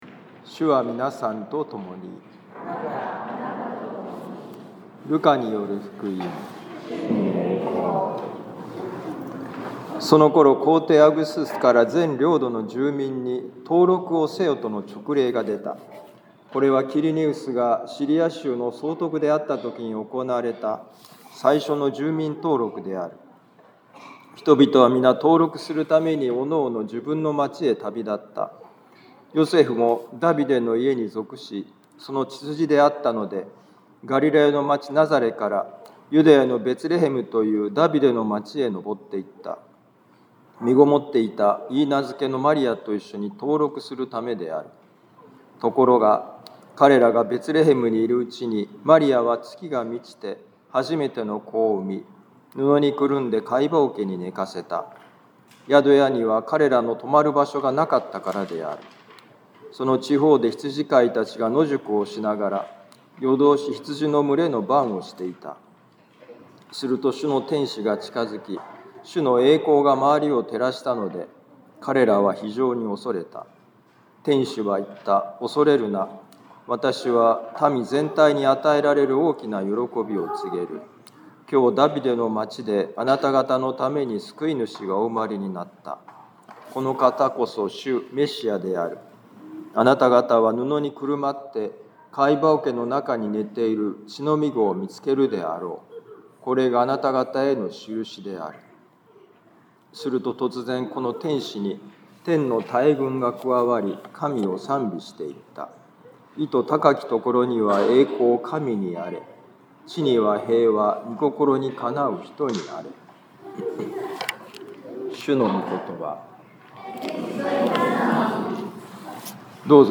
ルカ福音書2章1-14節「小さなわたしの捧げもの」2025年12月24日主の降誕夜半のミサ カトリック防府教会
子どもたちがたくさん参加しているミサです 福音朗読 ルカ福音書2章1-14節 そのころ、皇帝アウグストゥスから全領土の住民に、登録をせよとの勅令が出た。